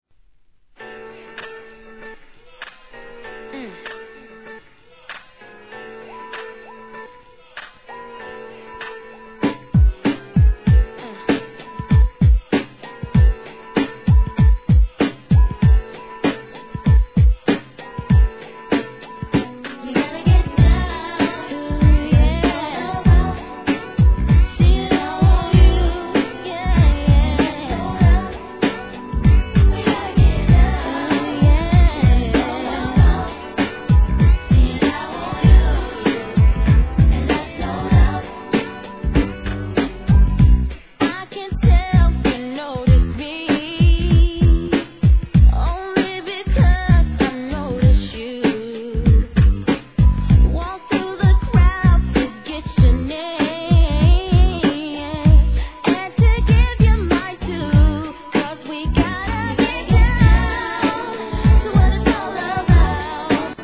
HIP HOP/R&B
フロア抜群のトラックにキャッチーなコーラスでCLUBでも定番に！